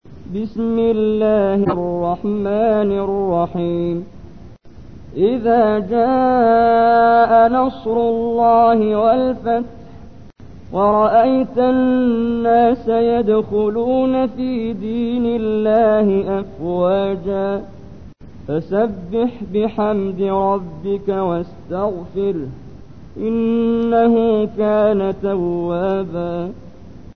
تحميل : 110. سورة النصر / القارئ محمد جبريل / القرآن الكريم / موقع يا حسين